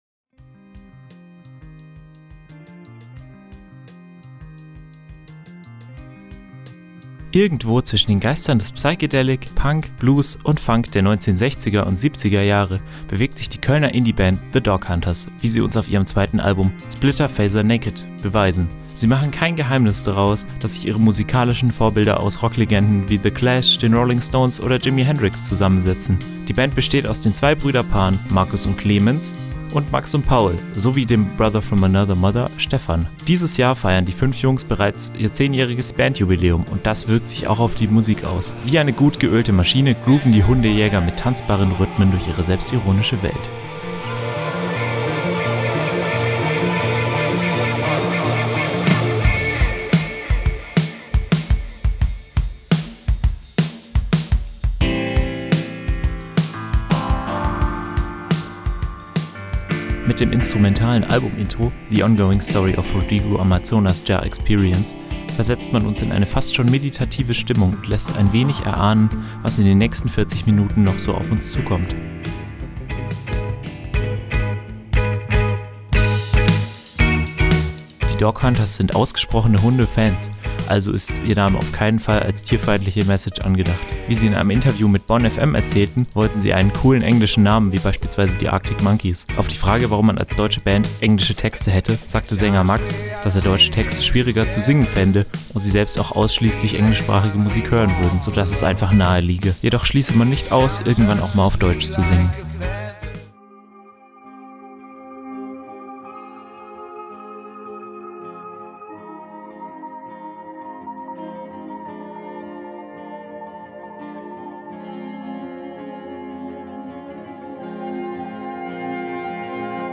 Irgendwo zwischen den Geistern des Psychedelic, Punk, Blues und Funk der 1960er und 70er Jahre bewegt sich die Kölner Indie-Band The DogHunters, wie sie uns auf ihrem zweiten Album „Splitter Phaser Naked“ gekonnt beweisen.
Dabei verlieren sie jedoch nie das Gefühl für verspielte Gitarrenriffs und prägnante Hooks, die, zusammen mit den Texten einen bittersüßen musikalischen Cocktail ergeben.